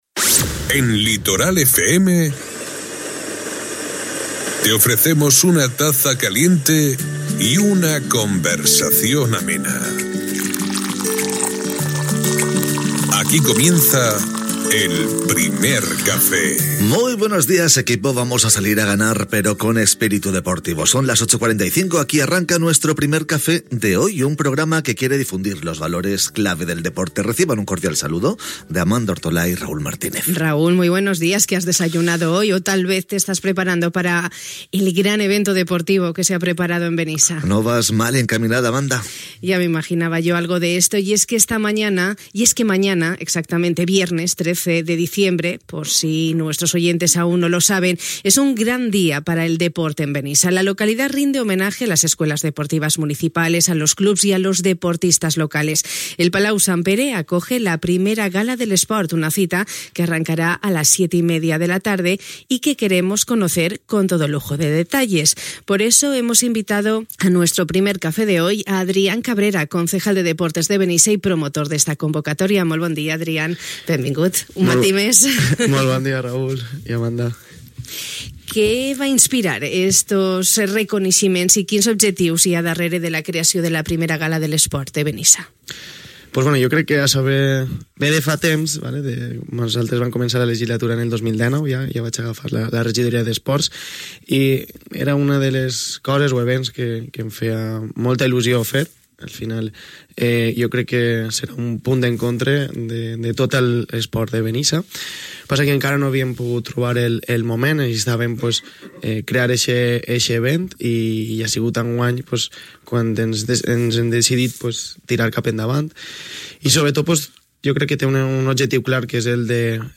Una cita que arrencarà a les 19.30 hores i que avui hem conegut el nostre Primer Cafè amb tot luxe de detalls amb el promotor d'aquesta iniciativa i regidor d'Esports, Adrián Cabrera.